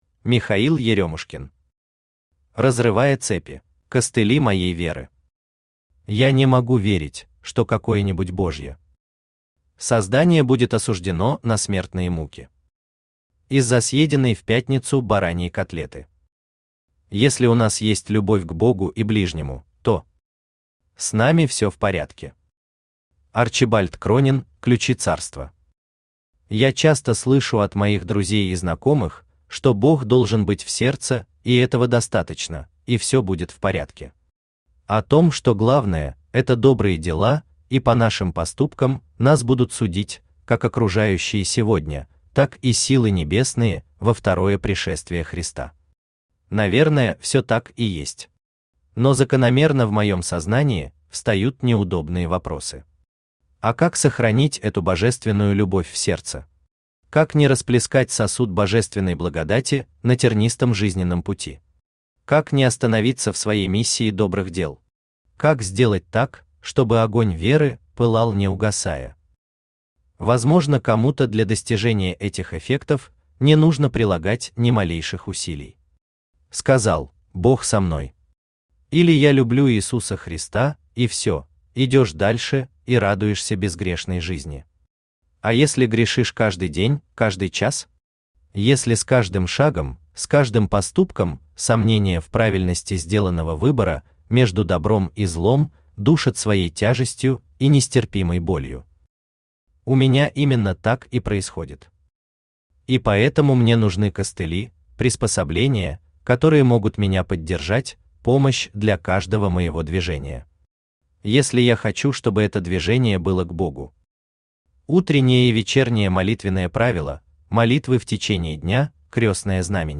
Аудиокнига Разрывая цепи | Библиотека аудиокниг
Aудиокнига Разрывая цепи Автор Михаил Анатольевич Еремушкин Читает аудиокнигу Авточтец ЛитРес.